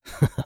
CK嘲弄02.wav
人声采集素材/男2刺客型/CK嘲弄02.wav